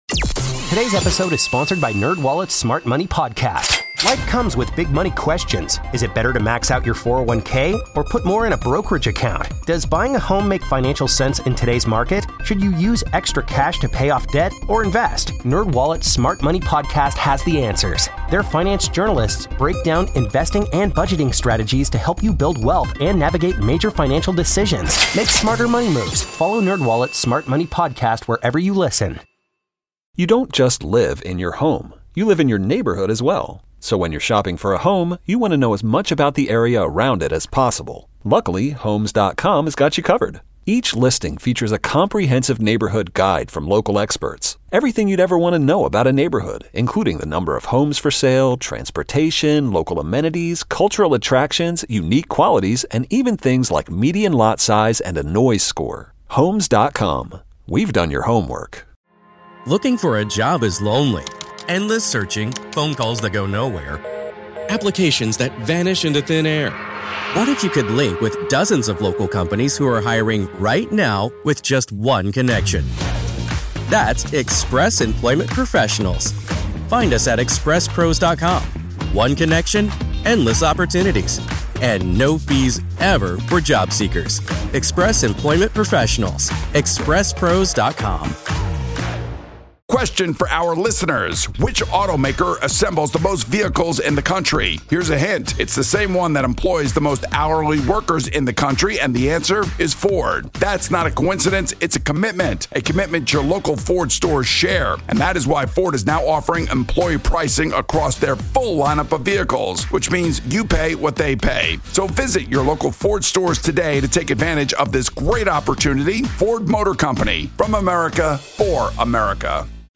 1 Interviews of the Week on 670 The Score: May 5-9 1:10:26